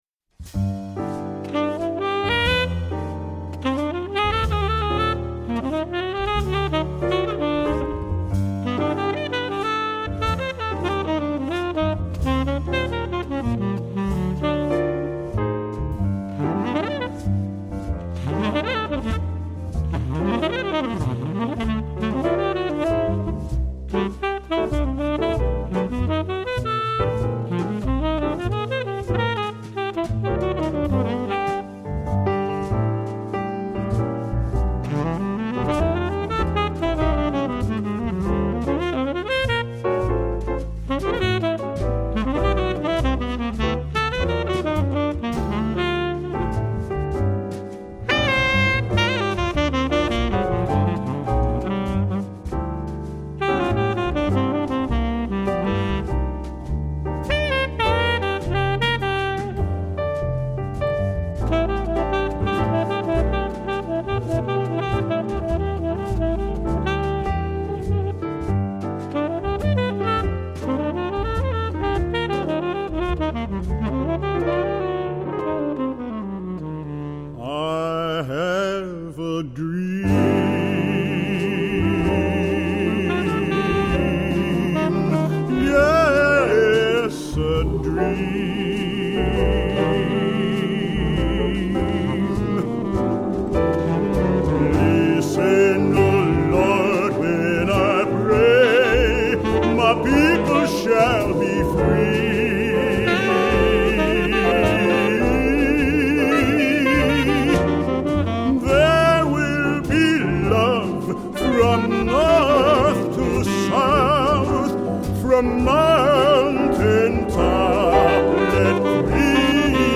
Choir
Tenor Saxophone